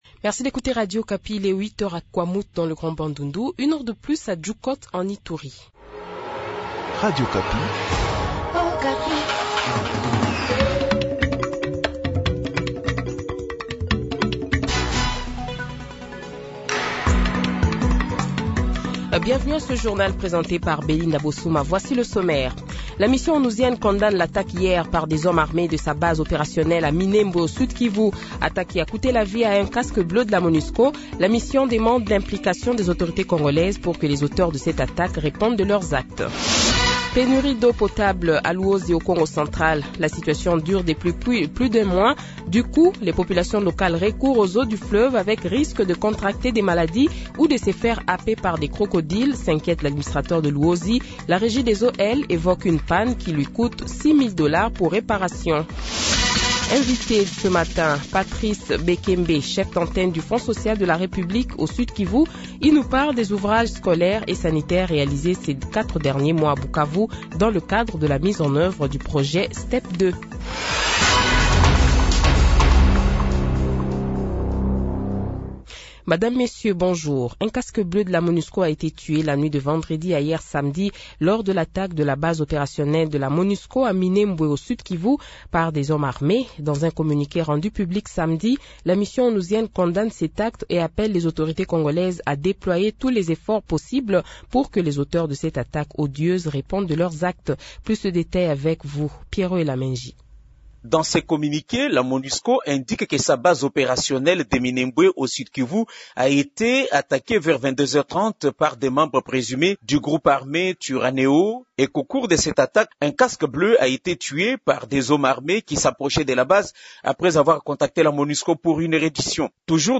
Le Journal de 7h, 02 Octobre 2022 :